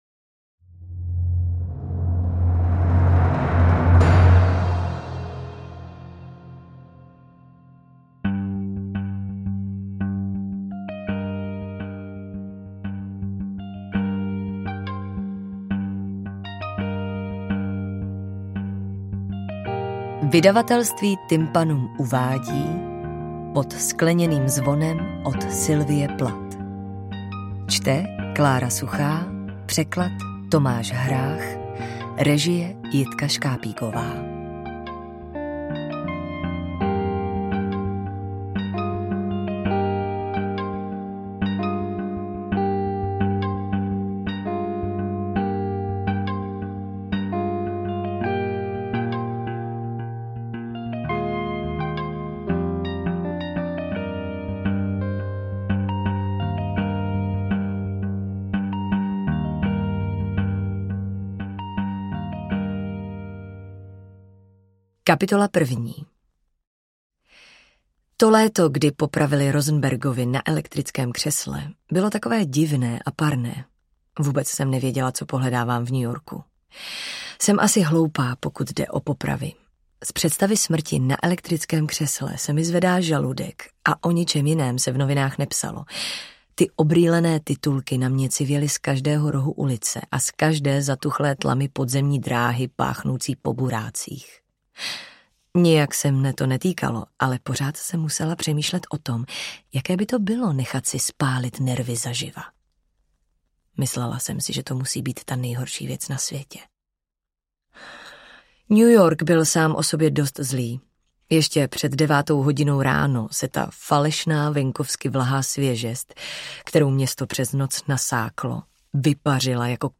AudioKniha ke stažení, 20 x mp3, délka 7 hod. 57 min., velikost 438,0 MB, česky